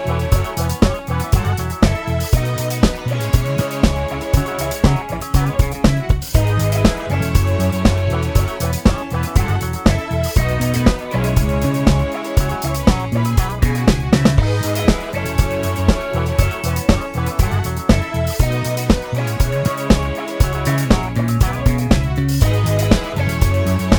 Minus Main Guitar Dance 4:10 Buy £1.50